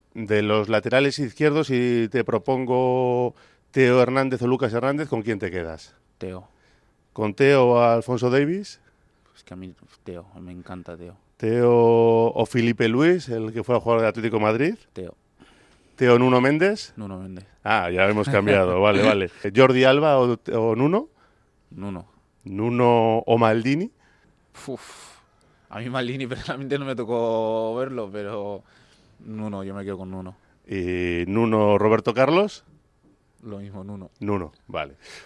Adama Boiro en una entrevista con Radio Popular em Lezama / RADIO POPULAR - HERRI IRRATIA